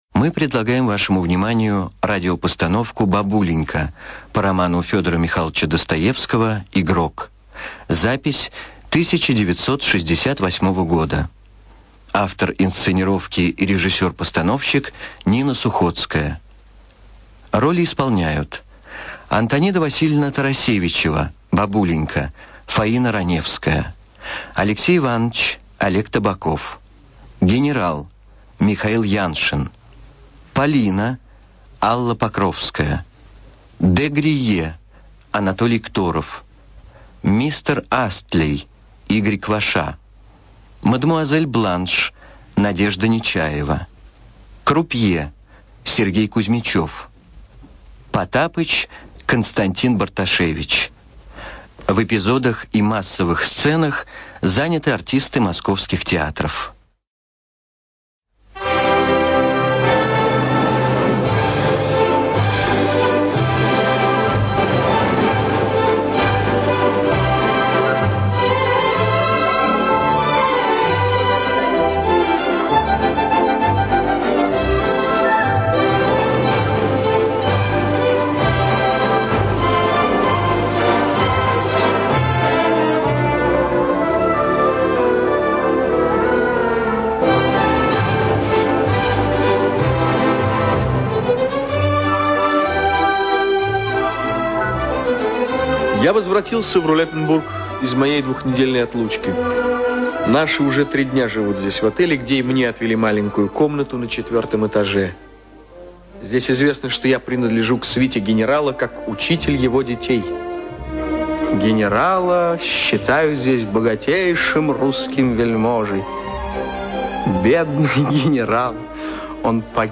Аудиоспектакль по роману "Игрок".
Роли исполняют: Ф.Раневская, М.Яншин, О.Табаков и др. артисты московских театров.